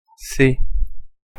Prononciation française, accent d'Ile de France.
French pronunciation.
Fr-C-fr-Paris.ogg